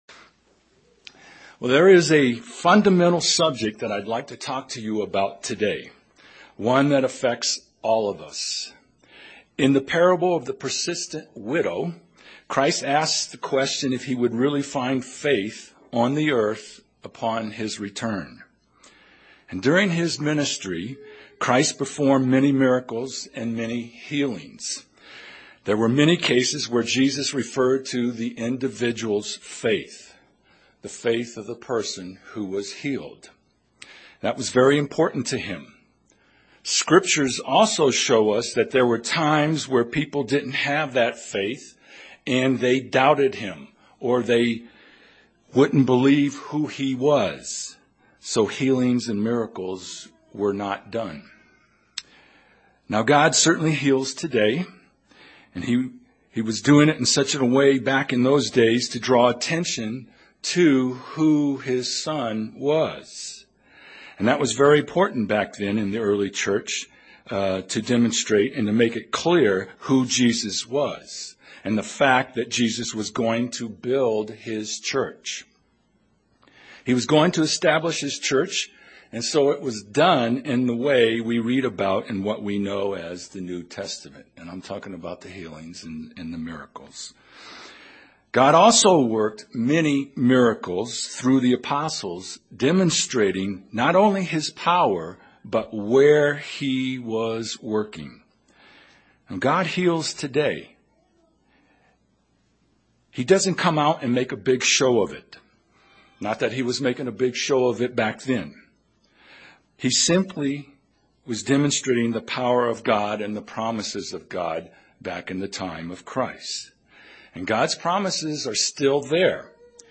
This sermon looks at a faith that is a way of life.